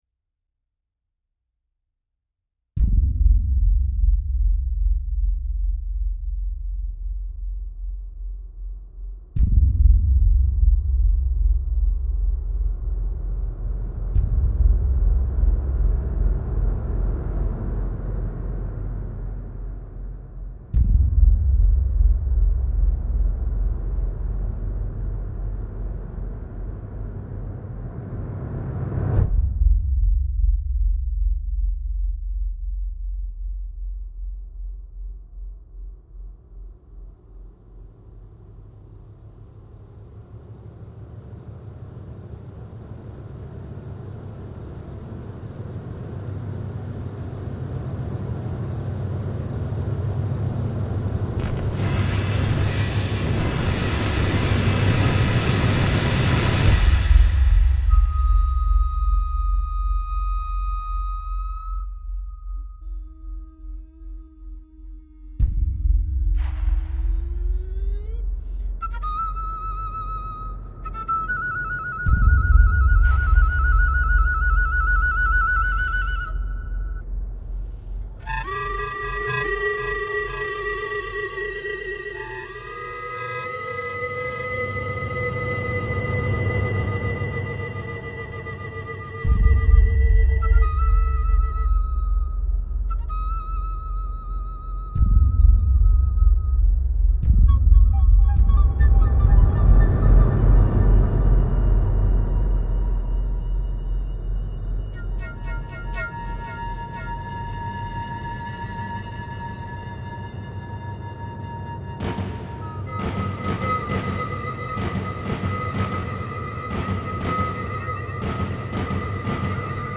…timelessness… for Chinese Flute in D and Max/MSP
at Bryan Recital Hall at BGSU